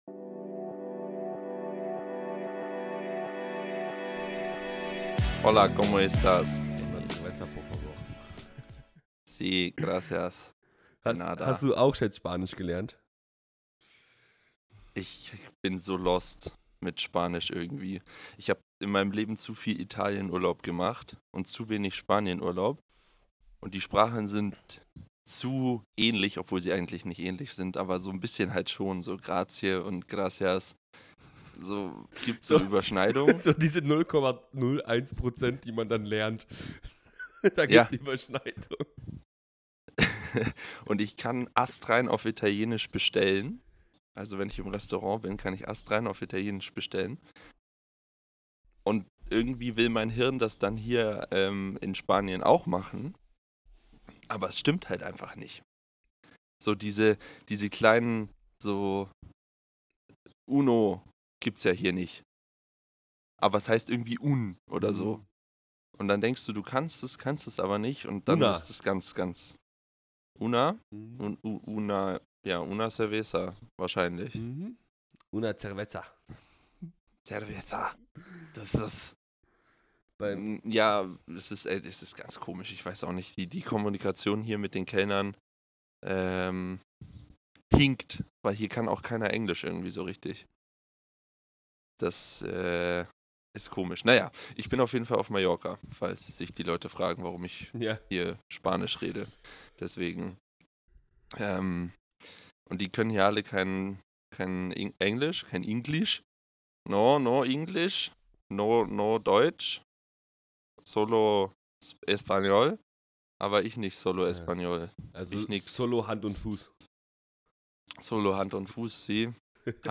hay pájaros que cantan de fondo.